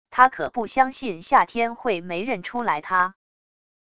他可不相信夏天会没认出来他wav音频生成系统WAV Audio Player